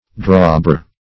drawbore - definition of drawbore - synonyms, pronunciation, spelling from Free Dictionary
Drawbore \Draw"bore`\, v. t.